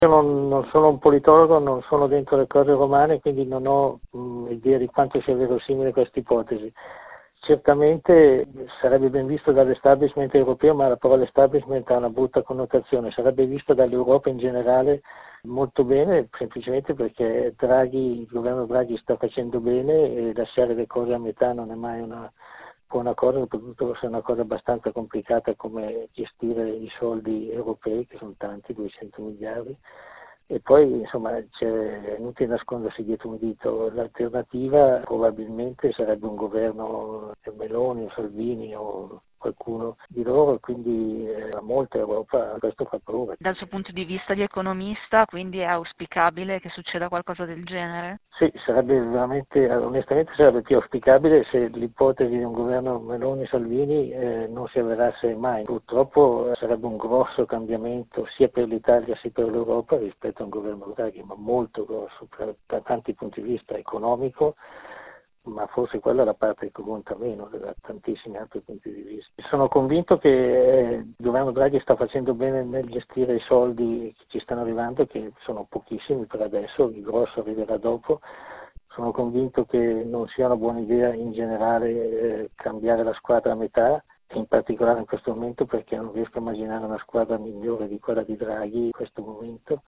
In questo giornale radio, iniziamo dando la parola a due economisti di parere opposto